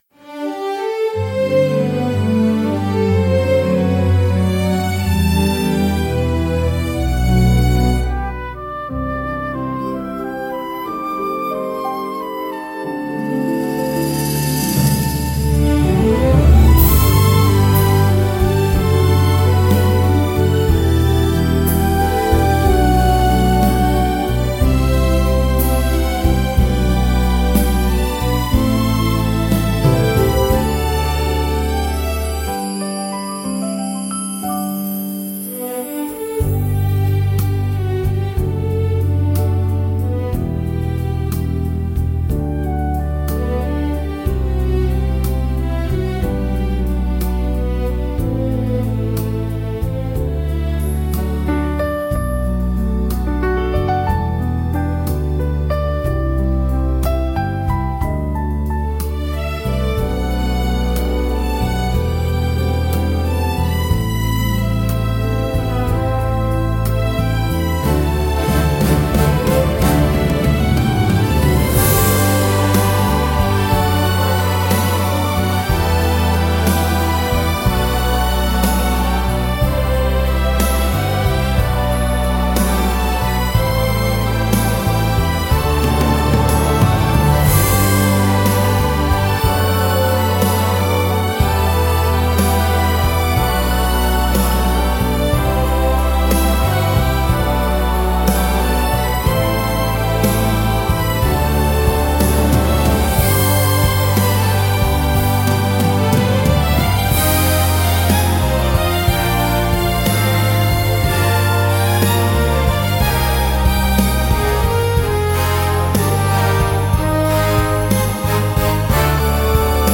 聴く人の心を温かく包み込み、特別な日の幸せな雰囲気を高める効果があります。